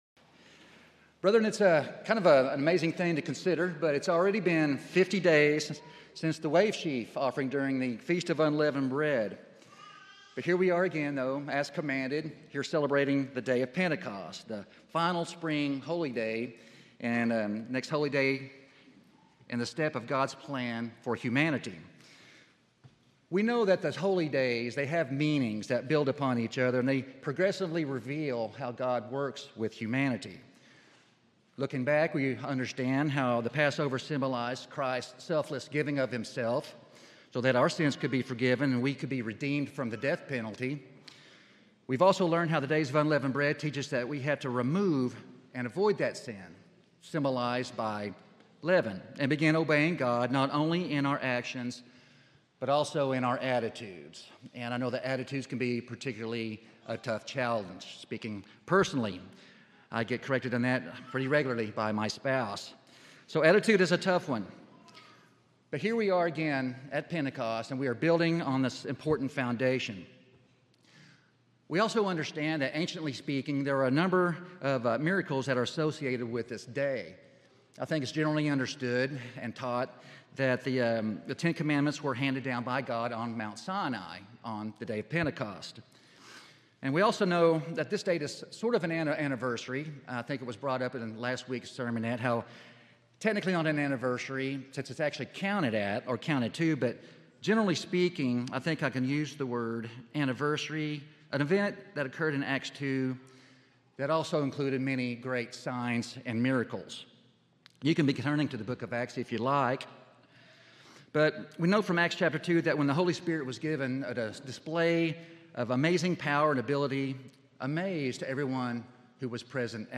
Sermons
Given in Oklahoma City, OK Tulsa, OK